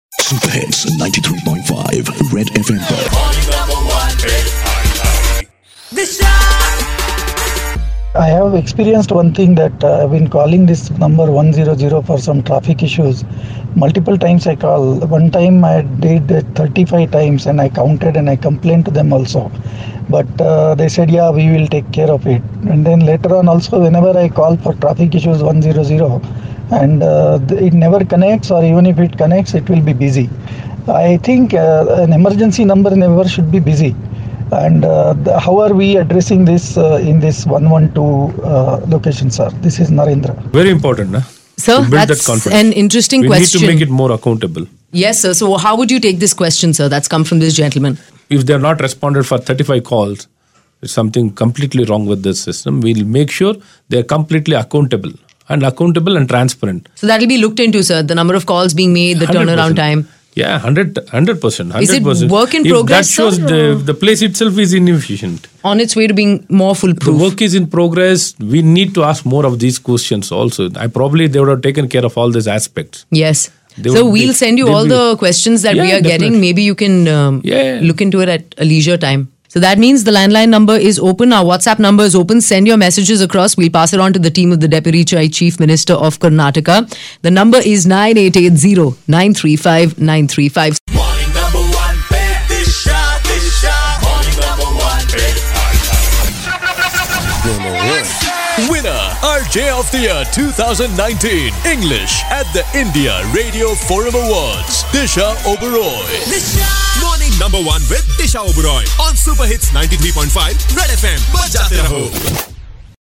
Dr. Ashwath Narayan answers listener queries